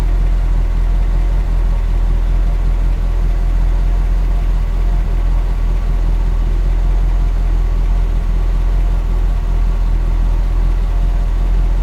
v8OLD.wav